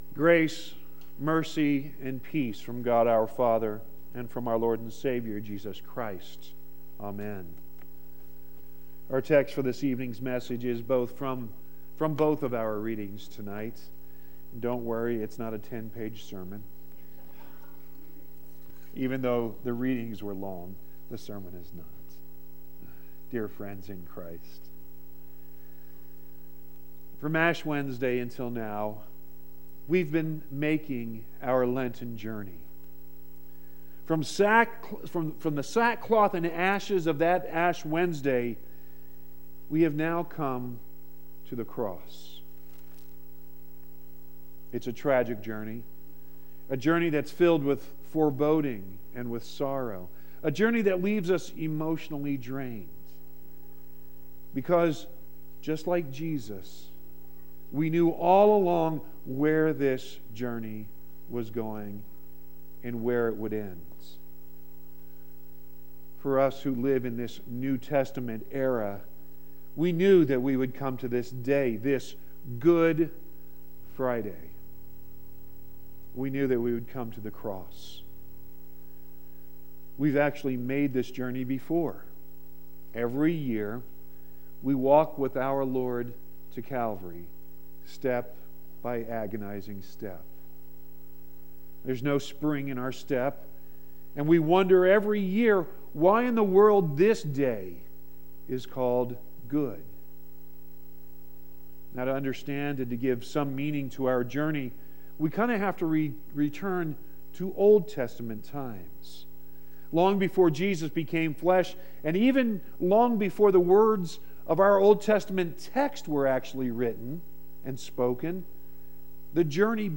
3-30-18-sermon.mp3